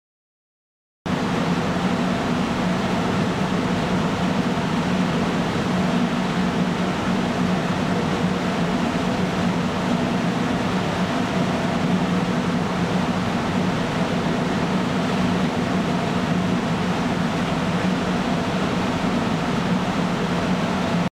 第14回多摩川第一発電所「水車発電機の回転音」
第14回 多摩川第一発電所「水車発電機の回転音」 小河内貯水池（水道局）に貯めた水を利用し発電する音です。 多摩川第一発電所では、小河内ダム（水道局）から取込む水の量と高低差が持つエネルギーを利用し、水車と発電機を回すことで発電をしています。